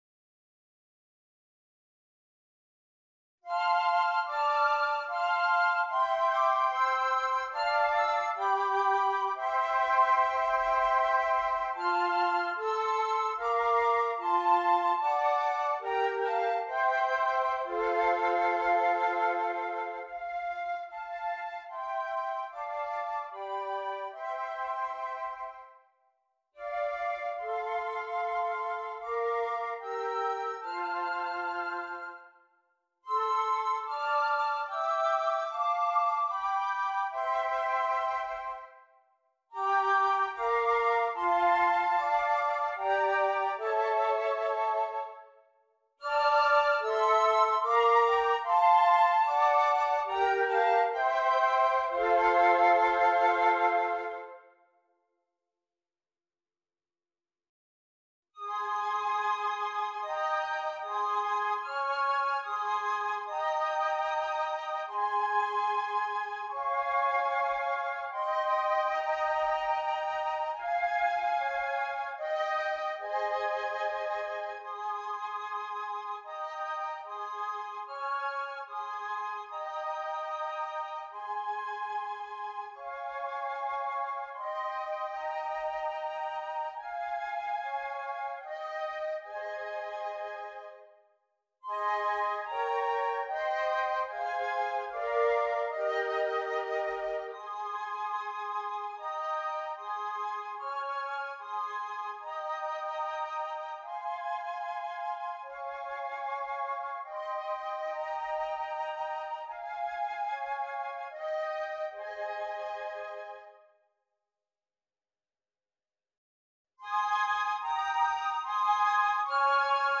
4 Flutes